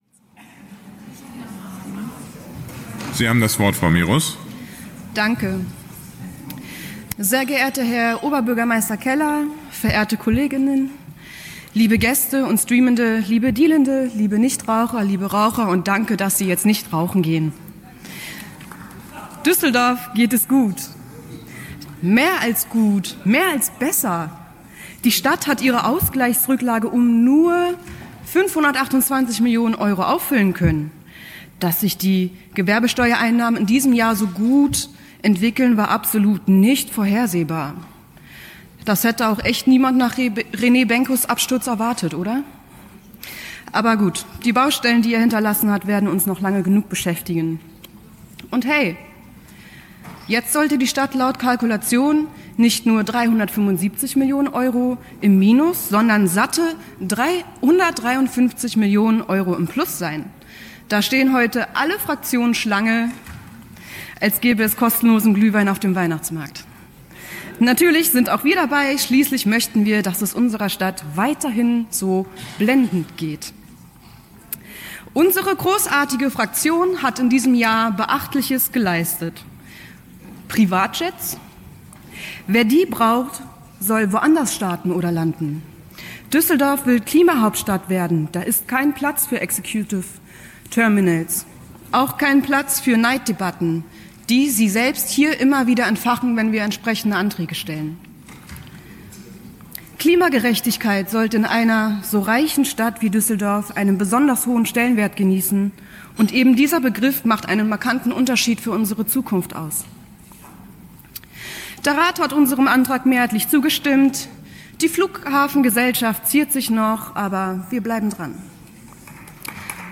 Unsere sehr gute Haushaltsrede! – Die PARTEI-Klima-Fraktion im Rat der Landeshauptstadt Düsseldorf
Ratsfrau Mique Mirus
( Hier die Rede als Audio-Mitschnitt. )